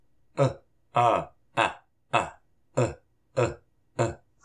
kutápatamuyumun vowels: u – á – a – a – u – u – u
vowel-sounds.mp3